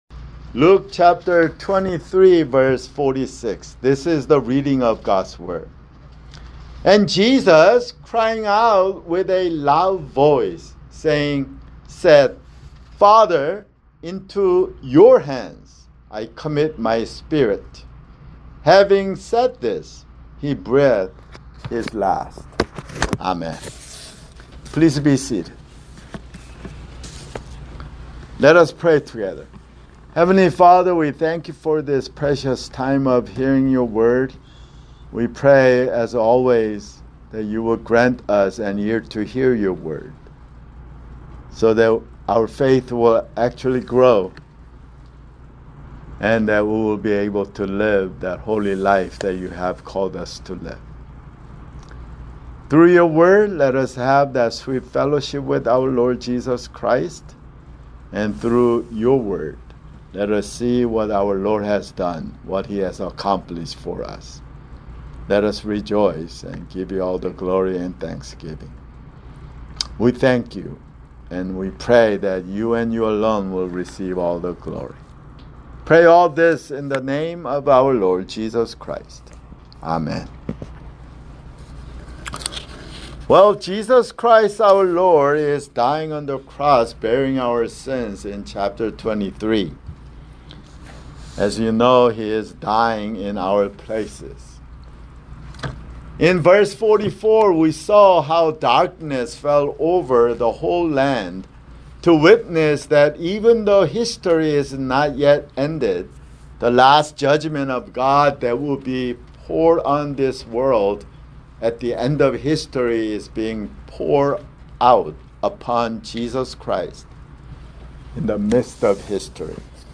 [Sermon] Luke (171)